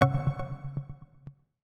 UIClick_Long Modern Echo 03.wav